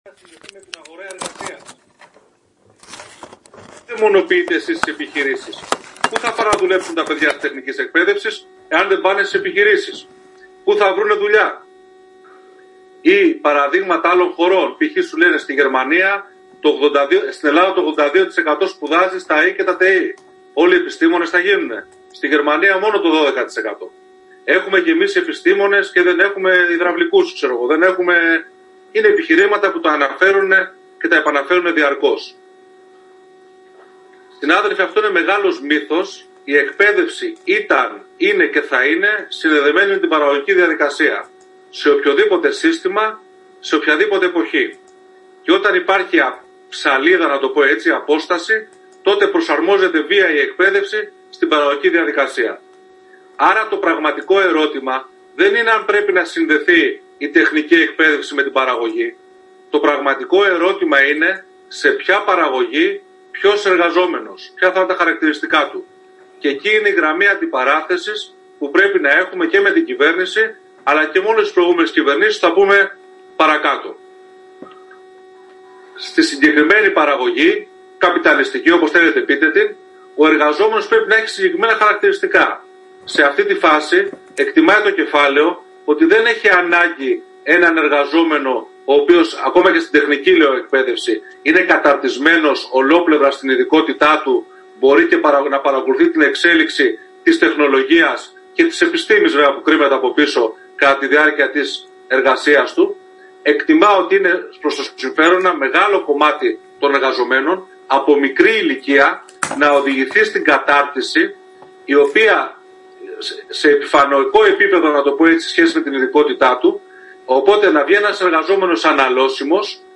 Διαδικτυακή συζήτηση για την Επαγγελματική Εκπαίδευση – Ανεξάρτητος